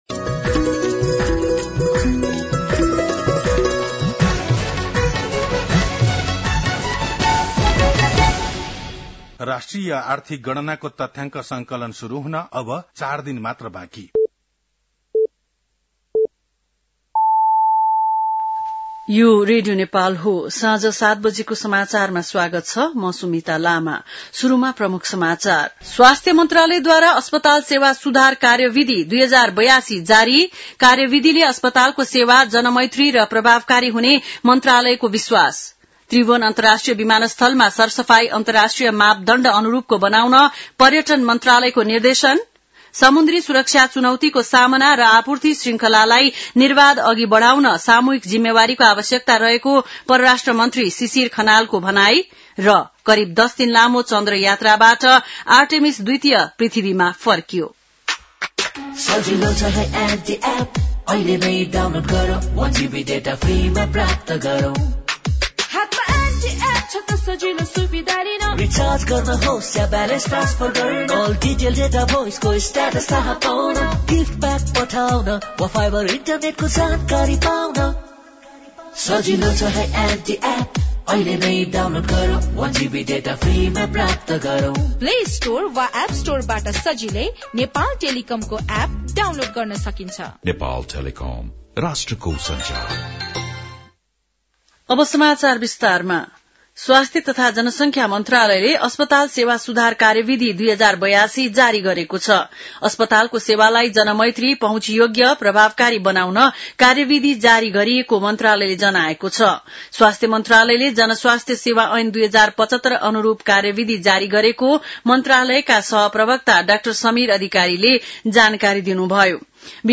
बेलुकी ७ बजेको नेपाली समाचार : २८ चैत , २०८२
7.-pm-nepali-news-1-2.mp3